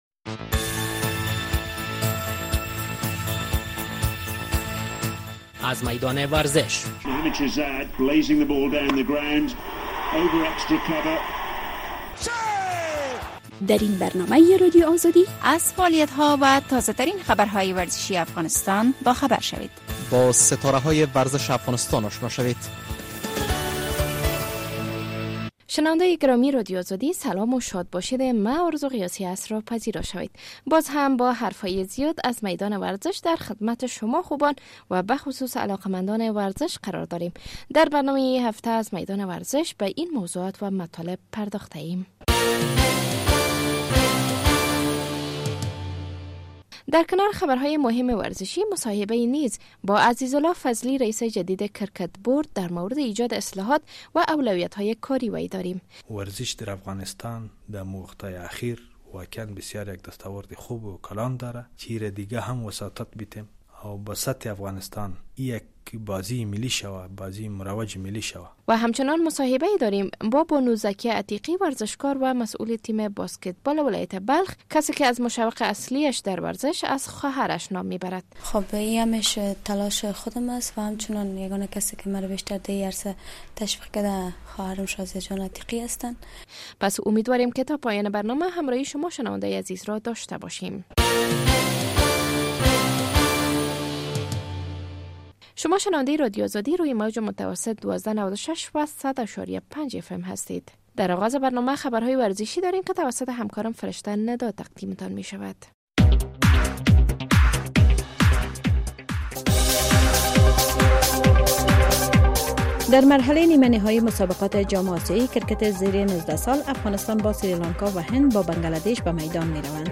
در برنامه این هفته در کنار خبرهای مهم ورزشی، مصاحبه‌ای با عزیزالله فضلی رئیس جدید کرکت بورد در مورد ایجاد اصلاحات و اولویت های کاری وی داریم.